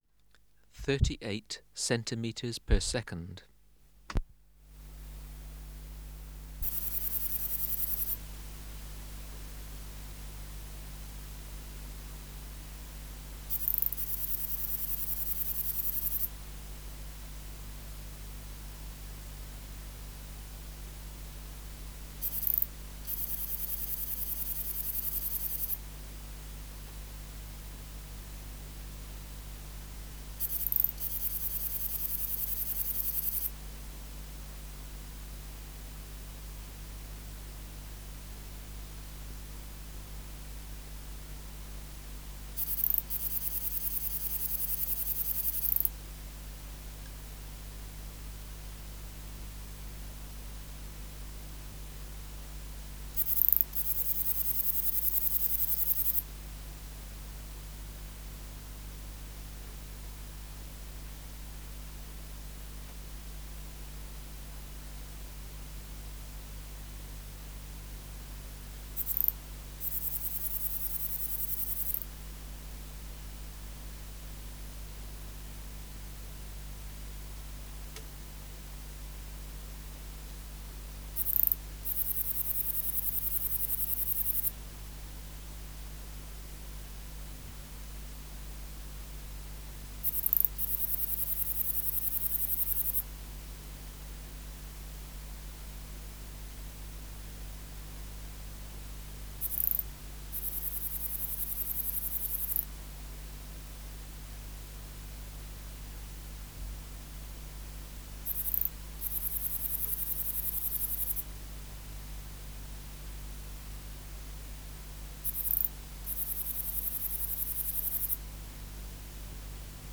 Natural History Museum Sound Archive Species: Platycleis affinis
Recording Location: BMNH Acoustic Laboratory
Reference Signal: 1 kHz for 10 s
Substrate/Cage: Large recording cage
Microphone & Power Supply: Sennheiser MKH 405 Filter: Low Pass, 24 dB per octave, corner frequency 20 kHz